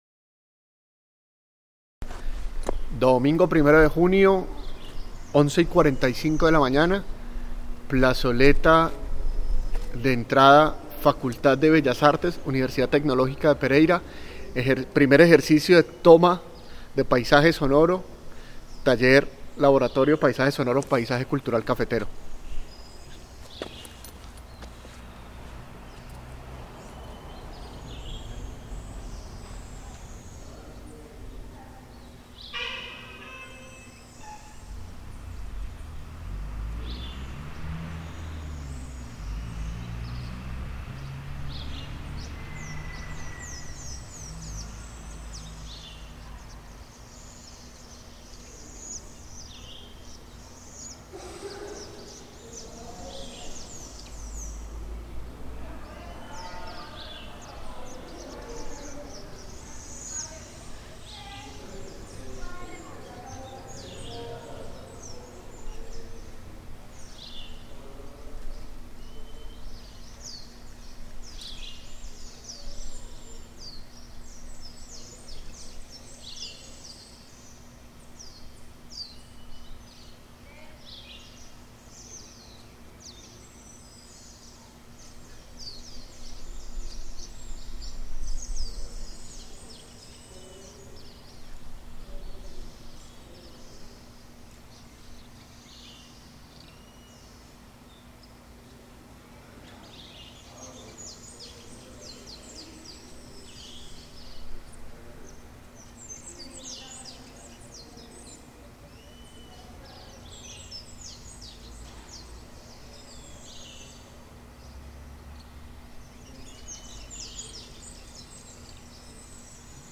Paisaje sonoro de la Universidad Tecnológica de Pereira. Hace parte del proyecto Paisaje Sonoro Paisaje Cultural Cafetero.